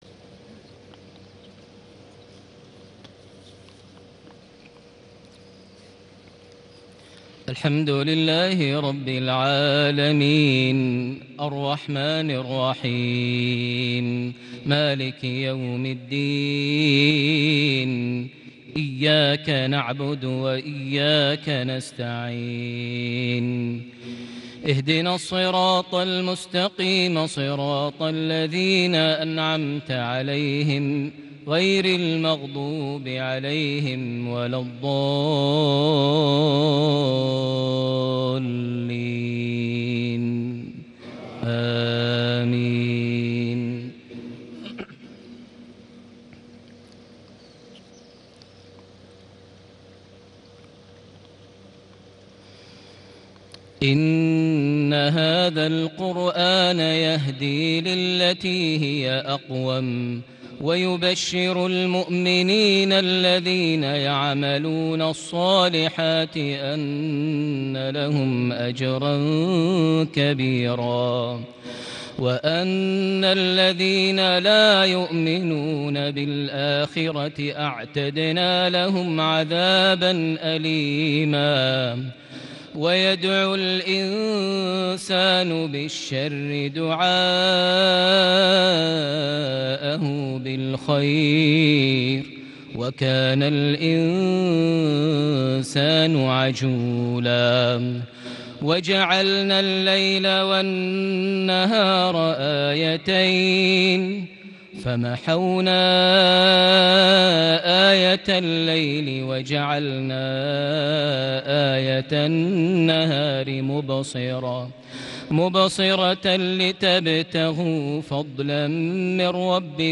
صلاة المغرب ١٥ ذو القعدة ١٤٣٨هـ سورة الإسراء ٩-١٧ > 1438 هـ > الفروض - تلاوات ماهر المعيقلي